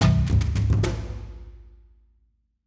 Tribal Drum.wav